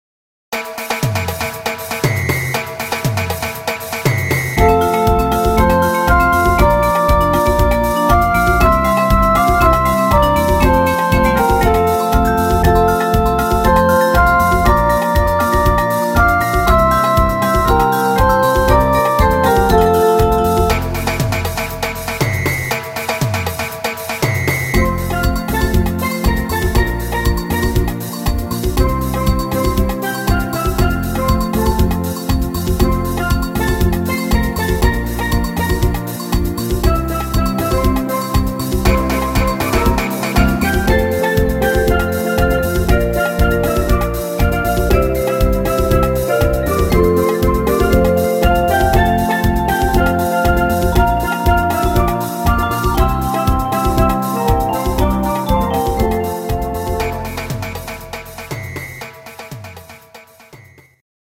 instr. Steel Drums